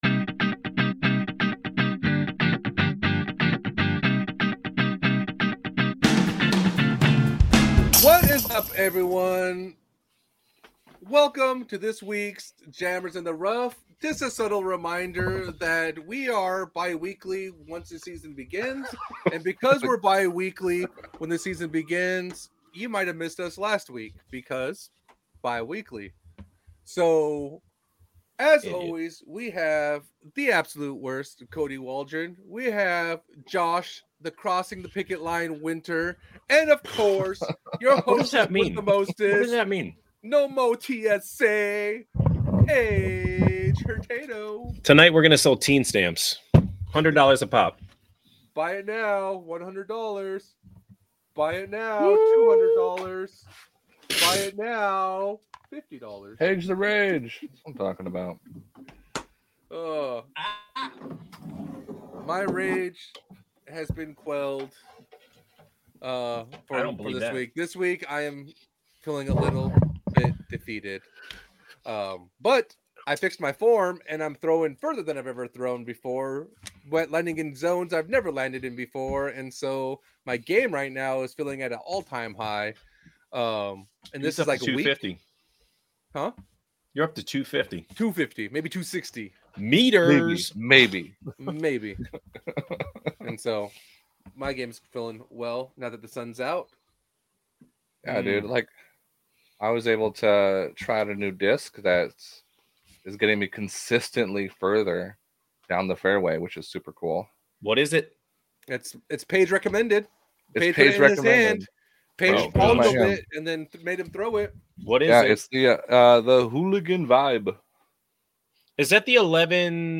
This is recorded live and raw in one take.